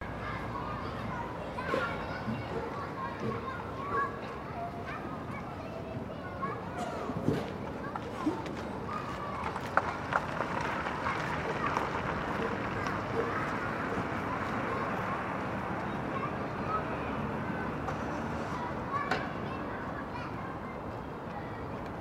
Звуки детского сада
Уличная площадка днем снято издалека